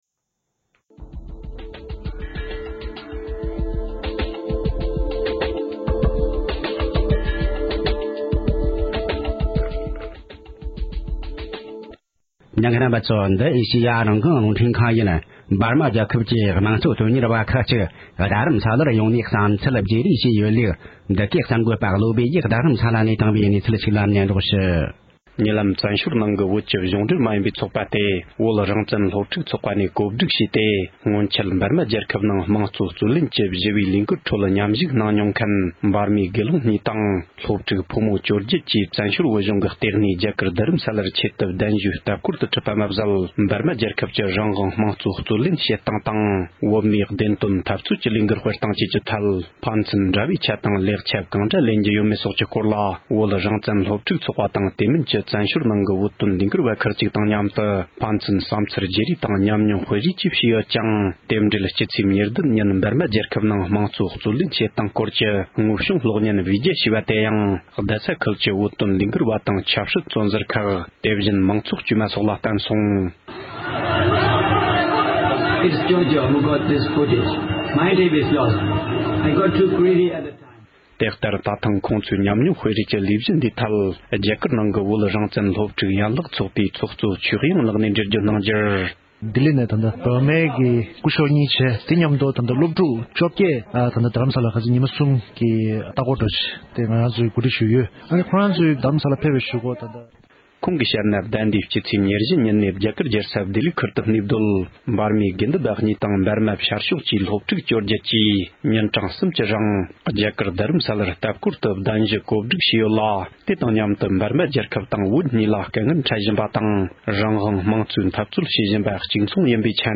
གསར་འགྱུར་ལ་གསན་རགོས།